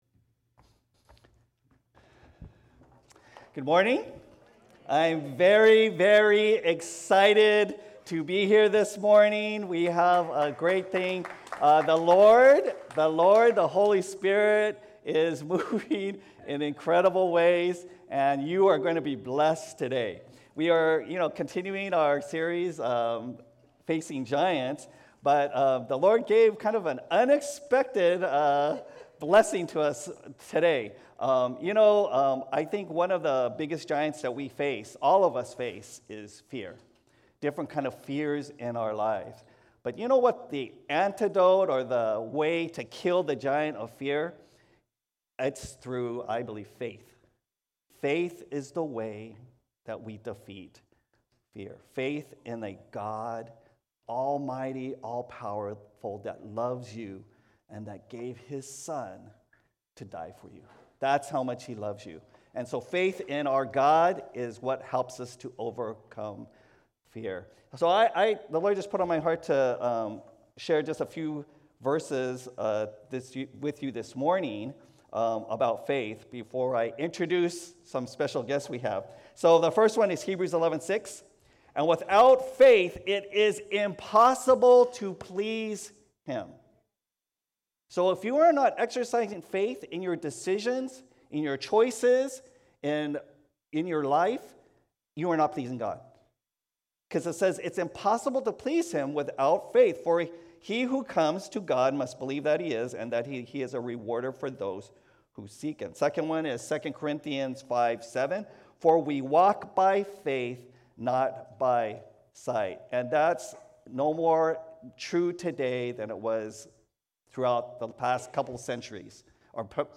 Special guests from YWAM share about their current missions trip and how it led them to Catalyst. Watch or listen to their stories of stepping out in faith.